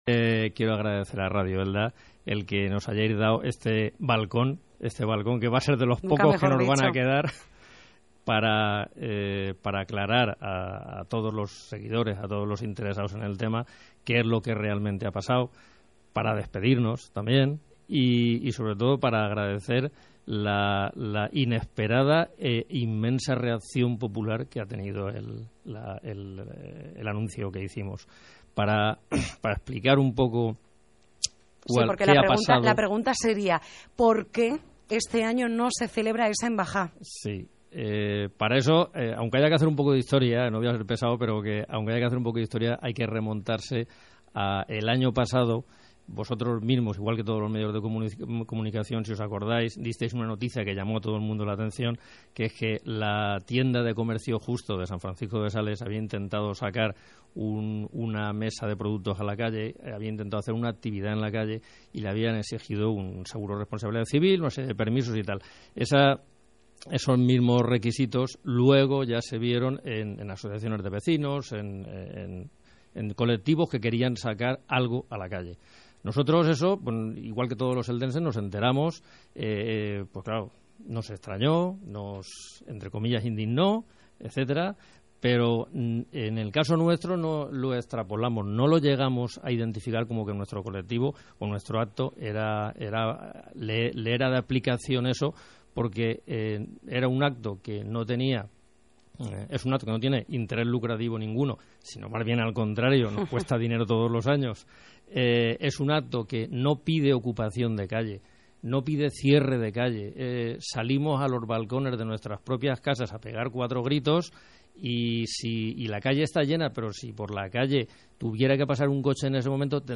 Dejamos la entrevista completa gracias a Radio Elda